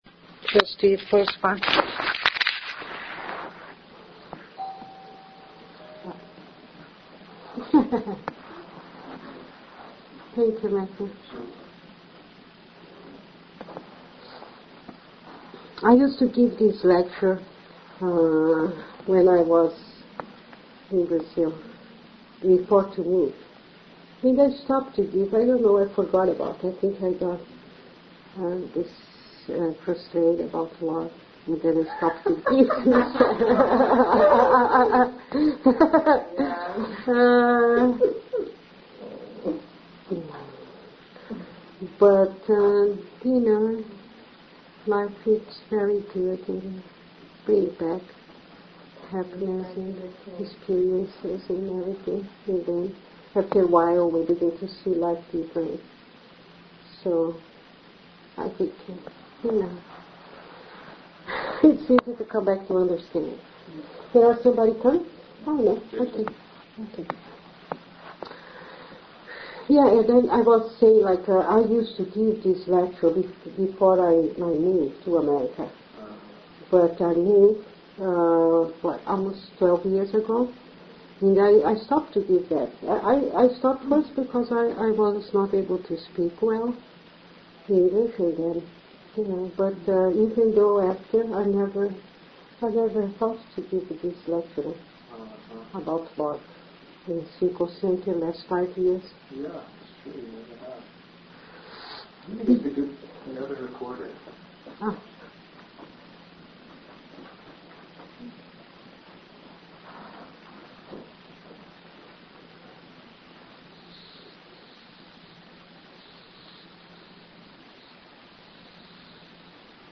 SM_Lecture_Oct07.mp3